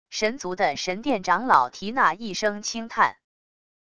神族的神殿长老缇娜一声轻叹wav音频